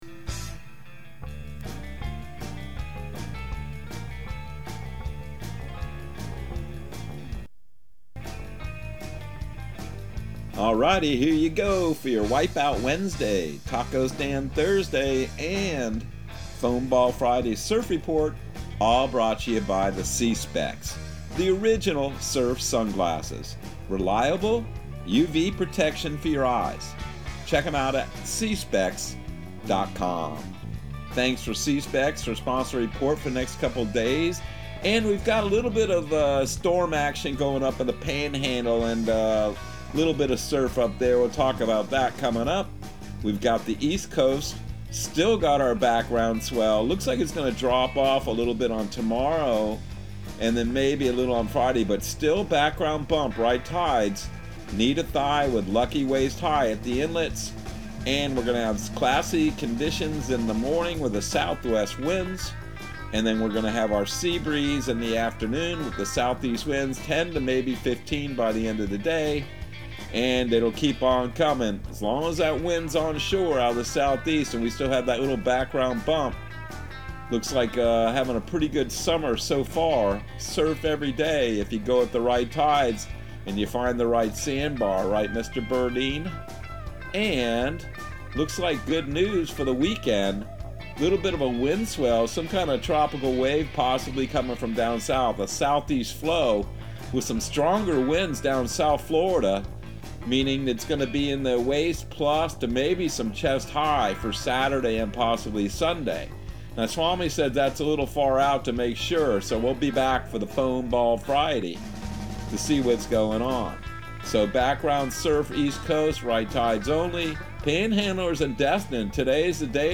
Surf Guru Surf Report and Forecast 07/13/2022 Audio surf report and surf forecast on July 13 for Central Florida and the Southeast.